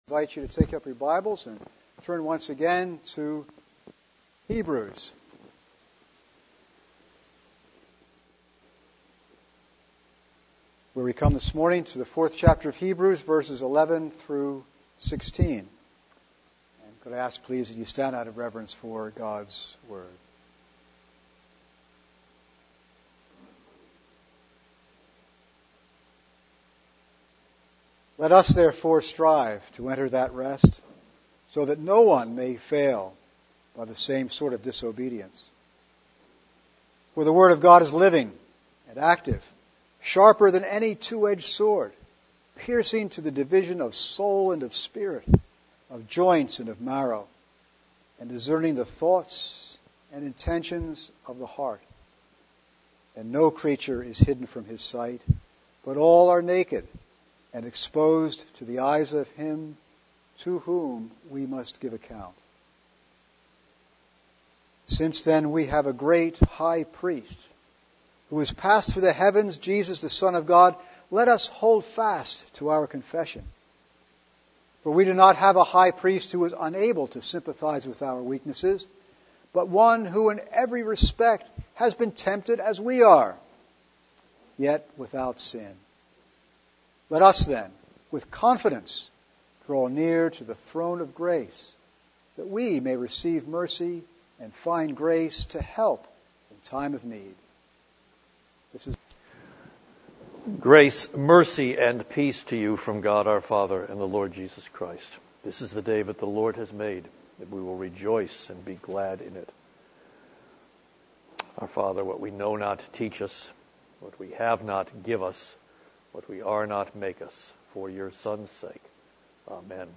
The Cornerstone Church - Sermons